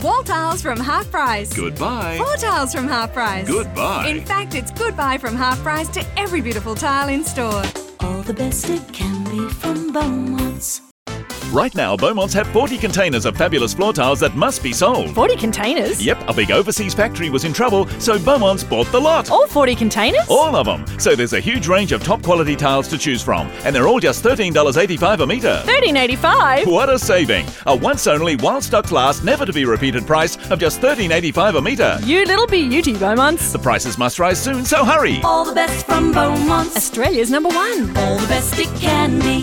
Female
English (Australian)
I'm described as natural and friendly and booked by studios looking for young & perky, a mum, energetic, smooth or seductive.
Television Spots
0724Commercials_-_retail.mp3